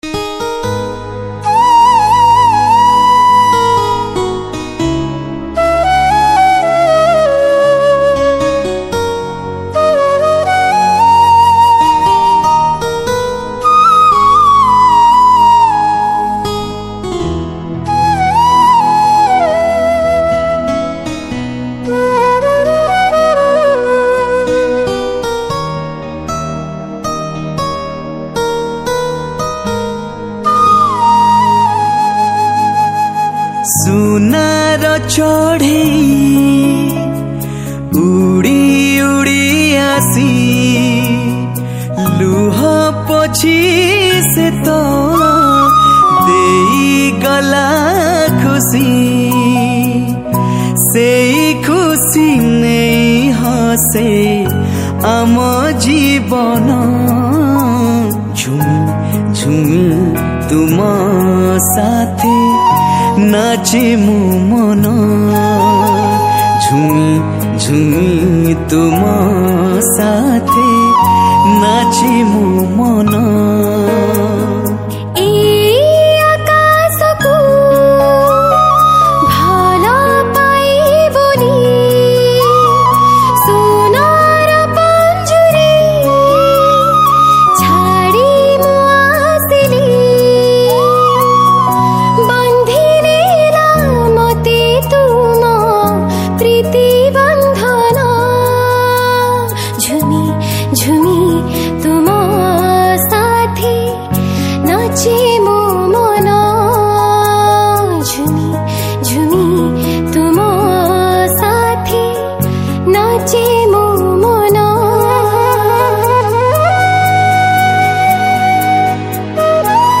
Odia Cover Song